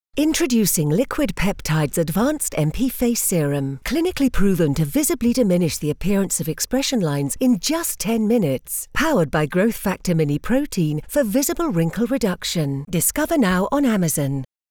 Velvety, smooth and sophisticated UK voice actor with a multitude of character voices!
Medik8 face serum TV advert